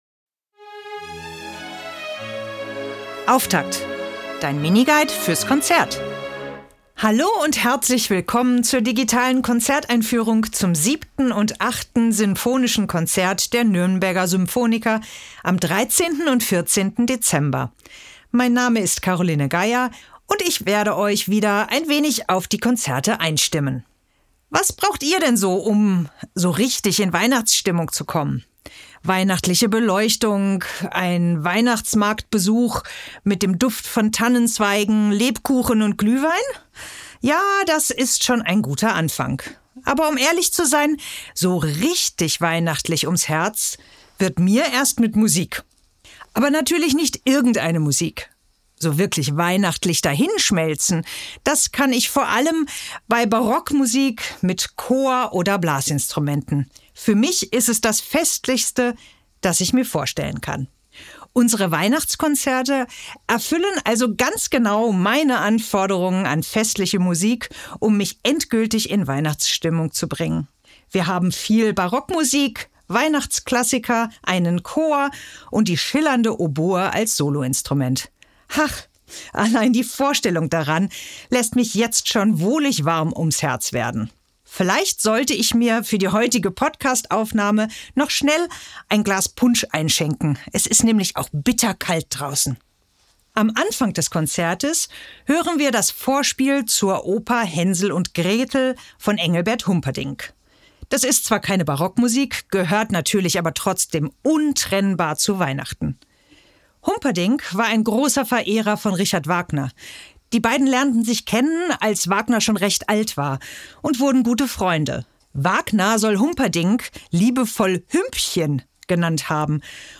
Musik Jingle: Serenade für Streicher, 2. Satz – Tempo di Valse‘